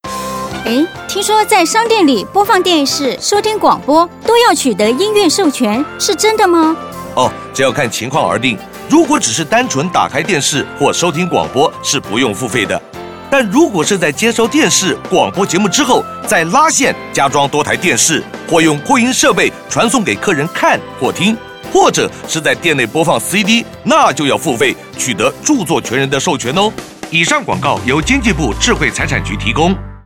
廣播廣告欣賞： 「營業場所音樂授權篇」(國語) (臺語) (客語)